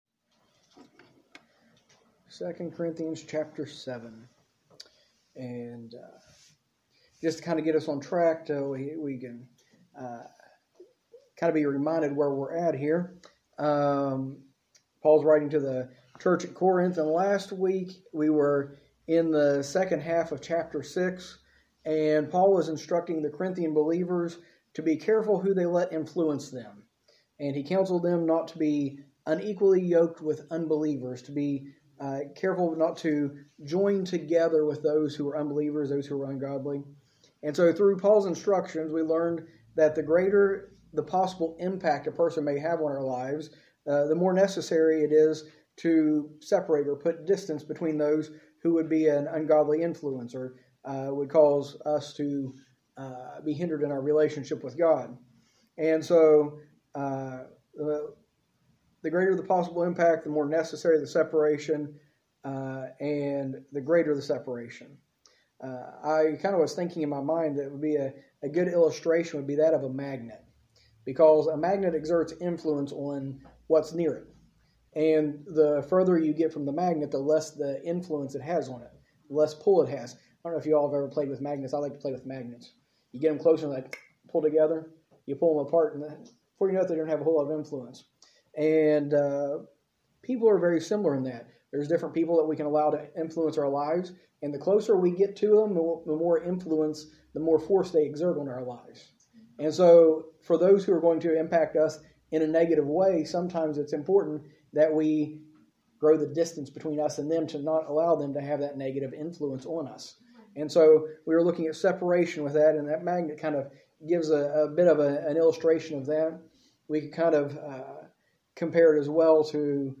A message from the series "2 Corinthians."